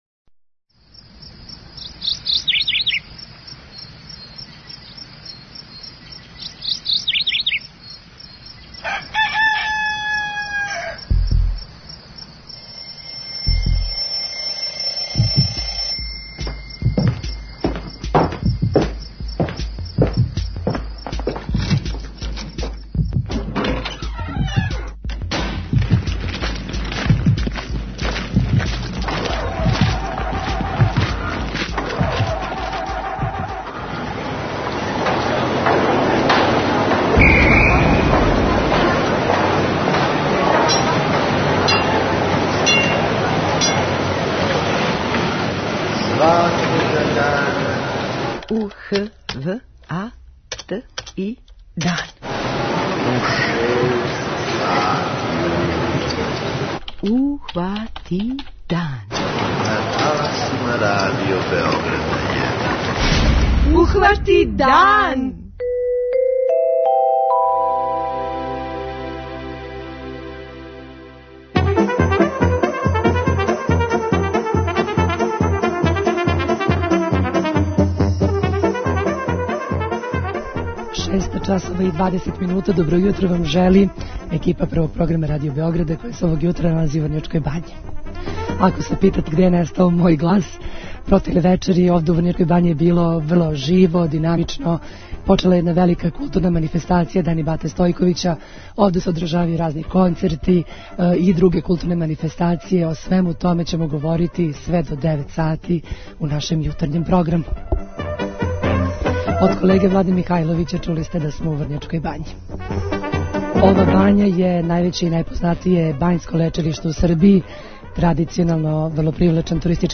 Јутарњи програм емитујемо уживо из Врњачке Бање!